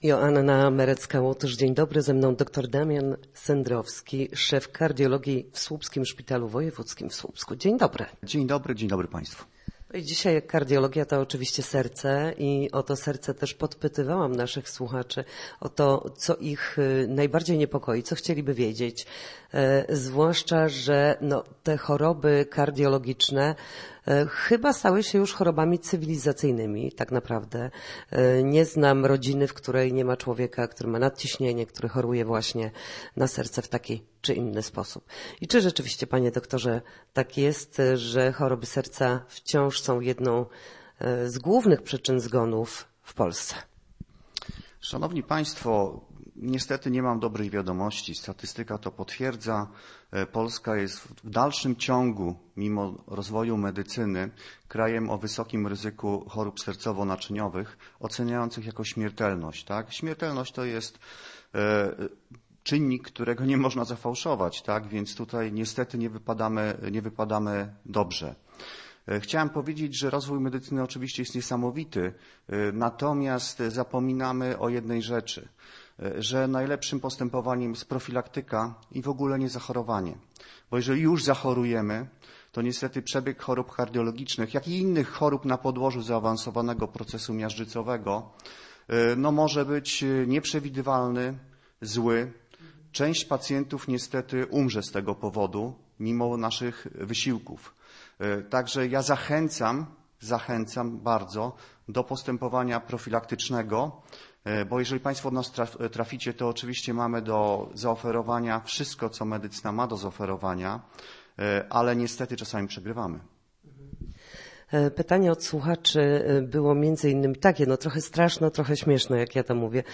Na radiowej antenie odpowiadał na pytania słuchaczy dotyczące chorób serca i profilaktyki, dzięki której nasze serce dłużej będzie biło zdrowe. Wyjaśnił, dlaczego choroby serca wciąż są jedną z głównych przyczyn zgonów.